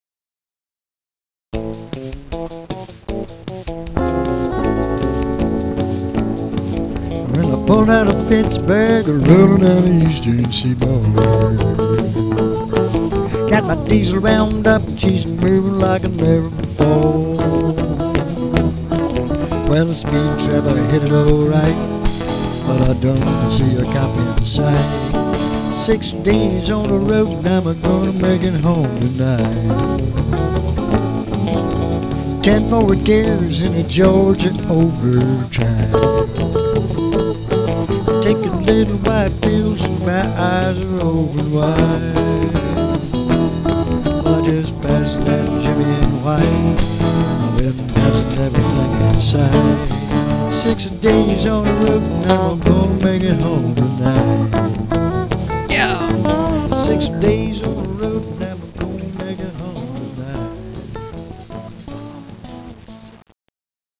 COUNTRY & WESTERN